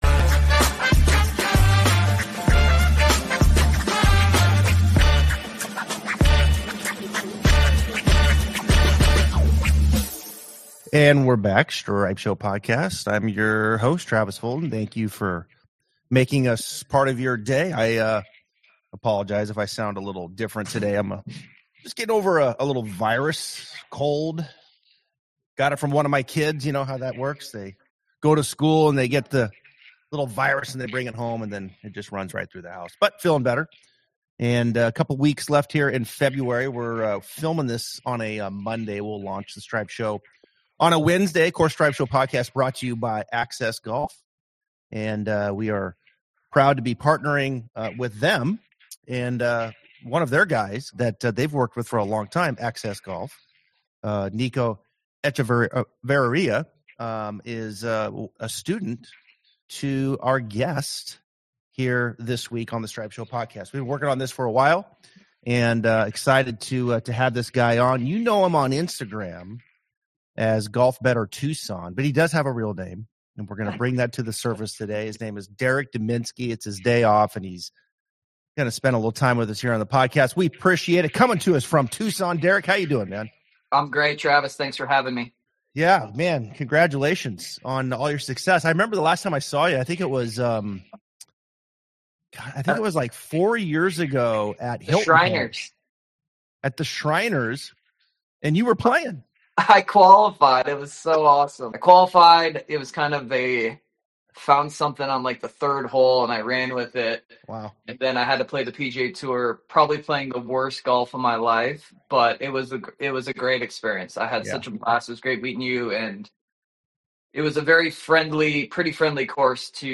golf podcast